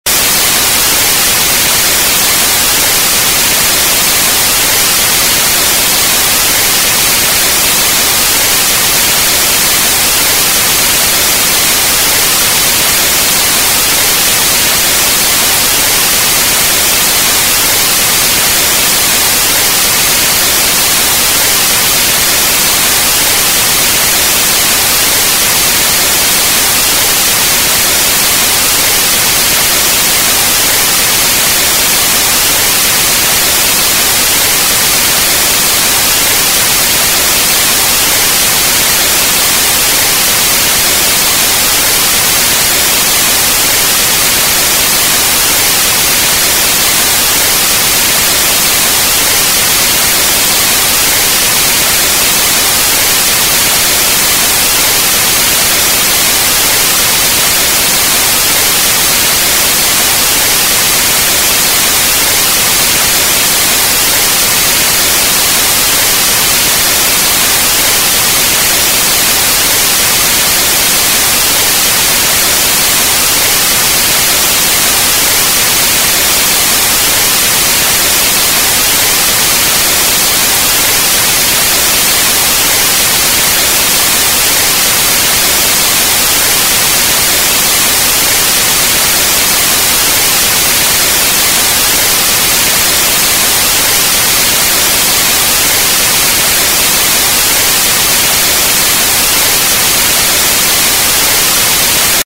جلوه های صوتی
دانلود صدای برفک تلویزیون از ساعد نیوز با لینک مستقیم و کیفیت بالا
برچسب: دانلود آهنگ های افکت صوتی اشیاء